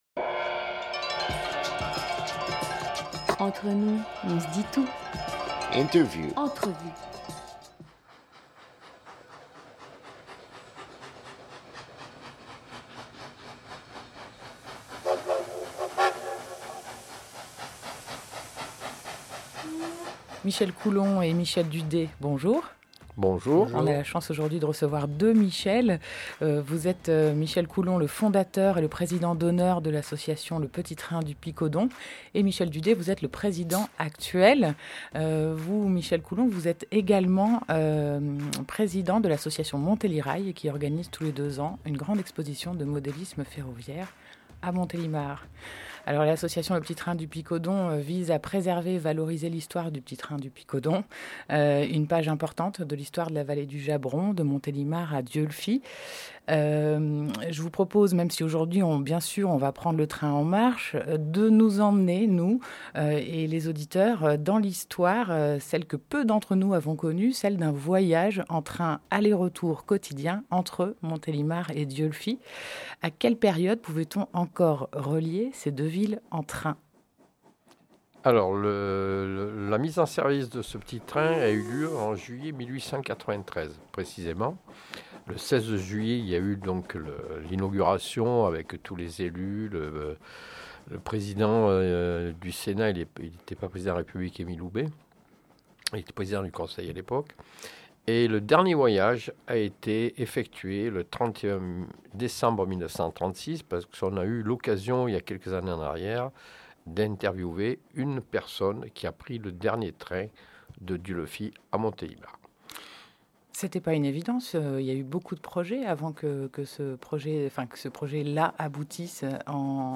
20 février 2018 12:00 | Interview